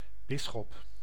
Ääntäminen
Synonyymit violet Ääntäminen France (Paris): IPA: [ɛ̃.n‿e.vɛk] Tuntematon aksentti: IPA: /e.vɛk/ Haettu sana löytyi näillä lähdekielillä: ranska Käännös Ääninäyte Substantiivit 1. bisschop {m} Suku: m .